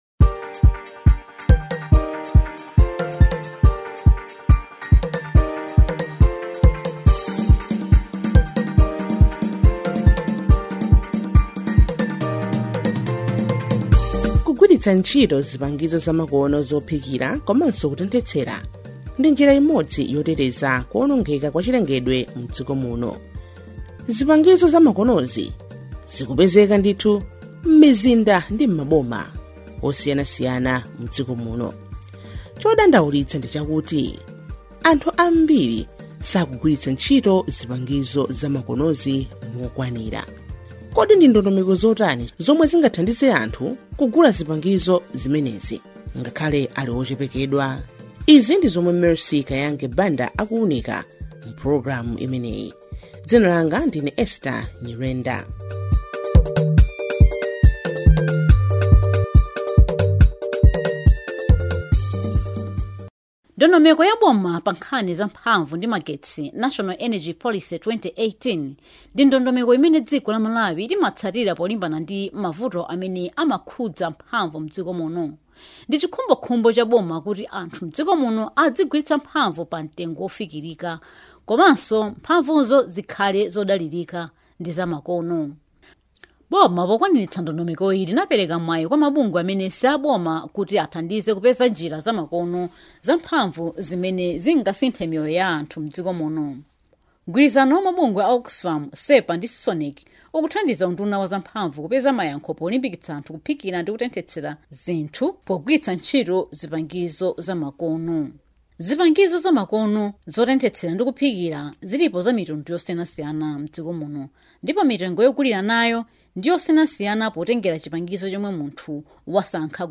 DOCUMENTARY 4